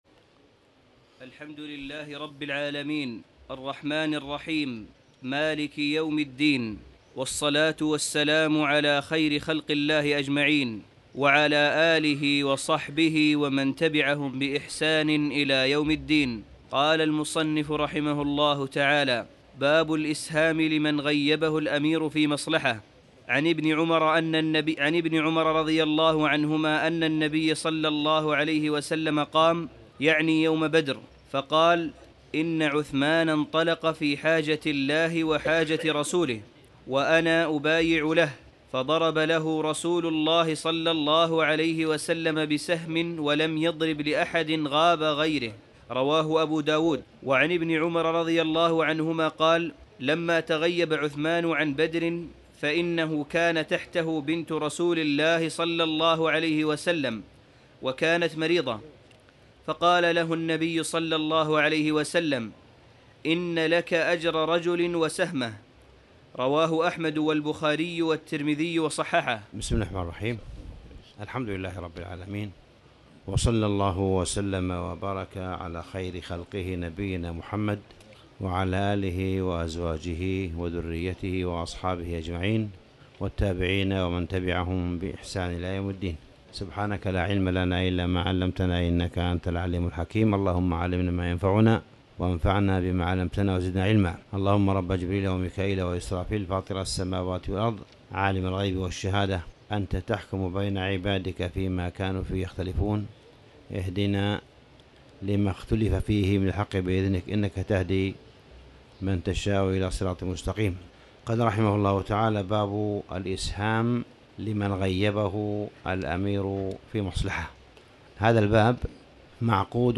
تاريخ النشر ١٤ صفر ١٤٣٨ هـ المكان: المسجد الحرام الشيخ: معالي الشيخ أ.د. صالح بن عبدالله بن حميد معالي الشيخ أ.د. صالح بن عبدالله بن حميد باب الإسهام لمن غيبه الأمير في مصلحه